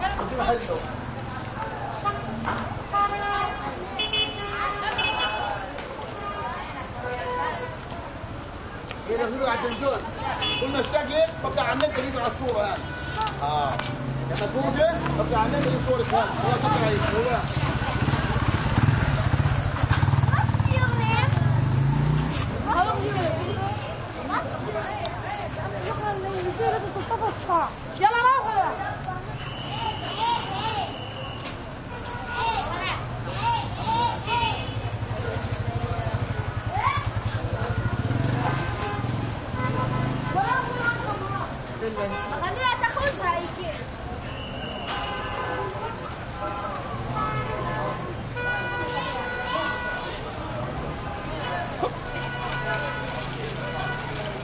印度 " 街头交通中的深喉喇叭声回响和一些亲密的声音 印度
描述：街道交通中等深喉咙鸣喇叭回声和一些亲密的声音India.flac
Tag: 交通 街道 喇叭 印度 honks 沙哑